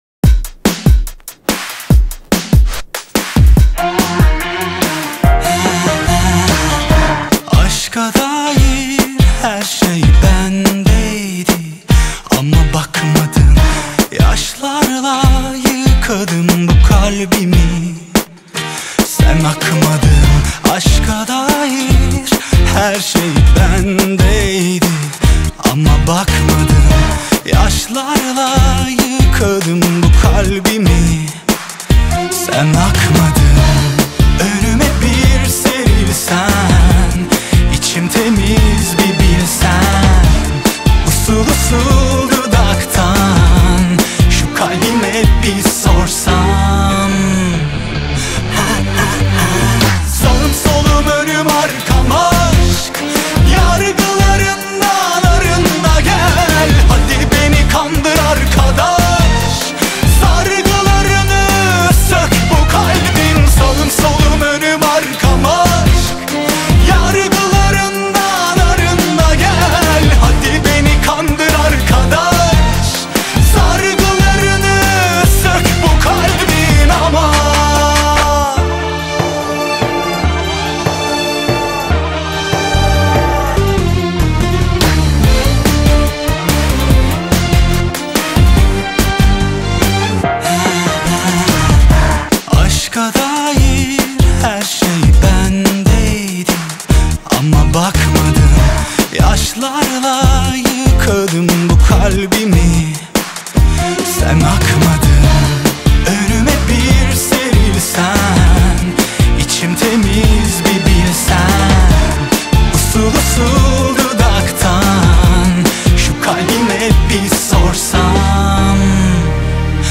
آهنگ ترکی جدید